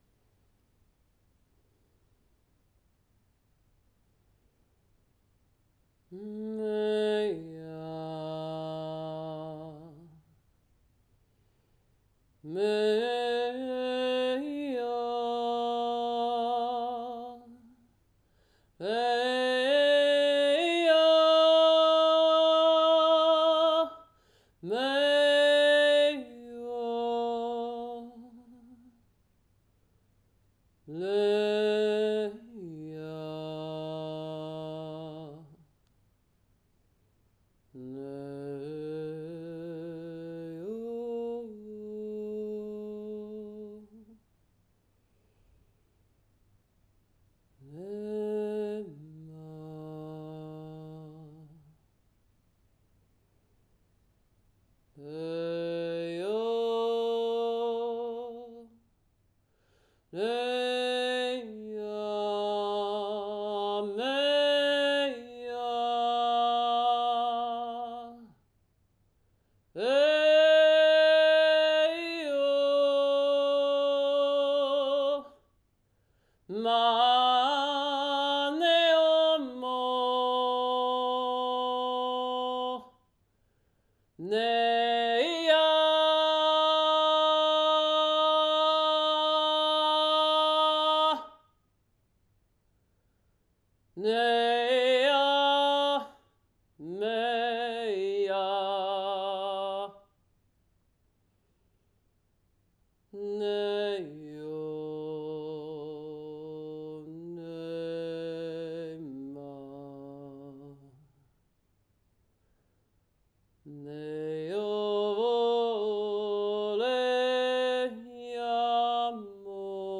DĚKOVACÍ stránka – léčivá zvukomalba – SKRYTÁ SÍLA – Terapie hlasem